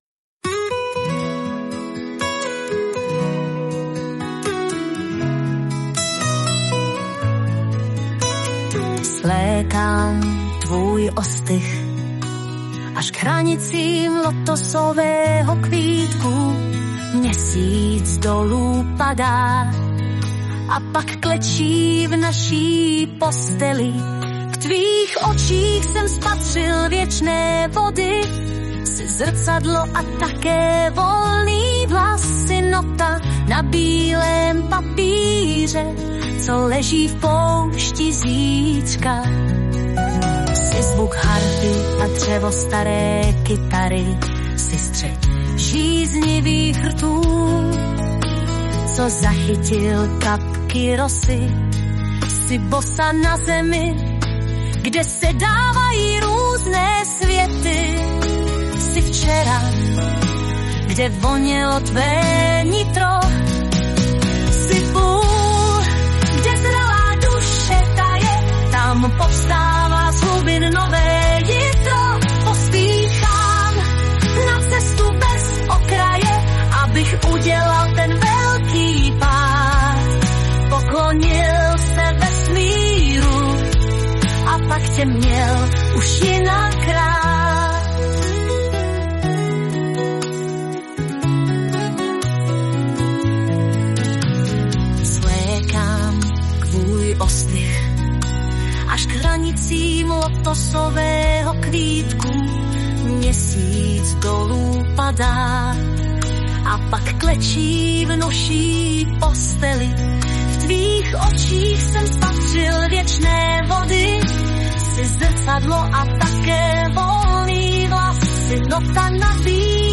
hudba a zpěv/ vokály s pomocí umělé inteligence/ AI